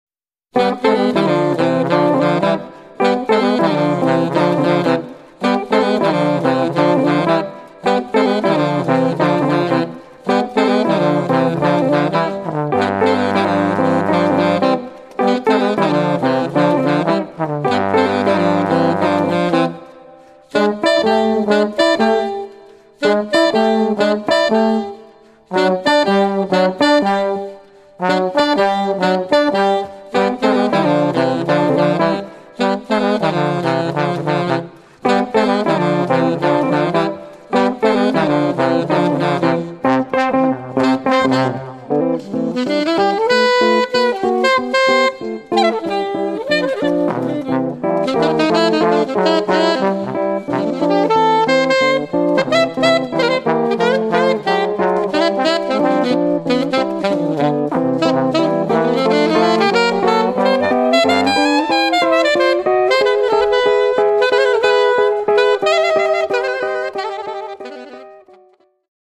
chitarre
trombone
sax e clarinetto